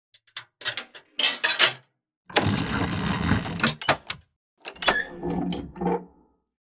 Coins.ogg